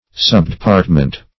Search Result for " subdepartment" : The Collaborative International Dictionary of English v.0.48: Subdepartment \Sub`de*part"ment\, n. A subordinate department; a bureau.